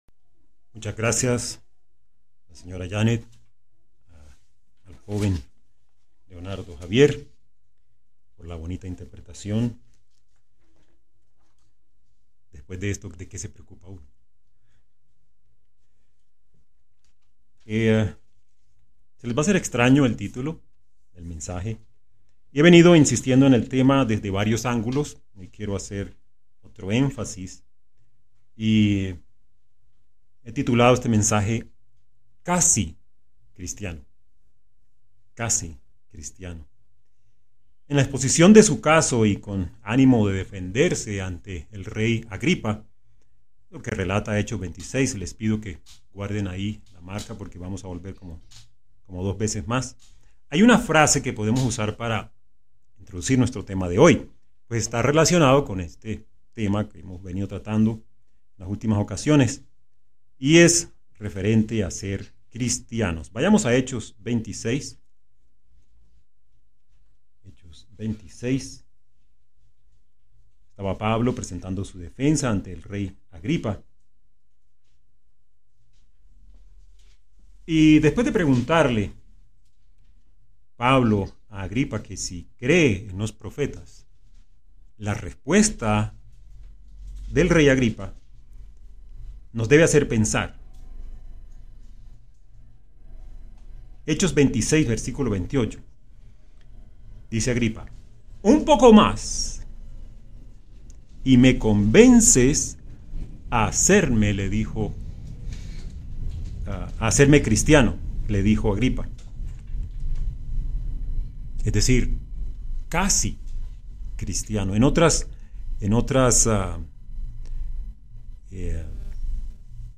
Sermones
Given in Santa Rosa del Sur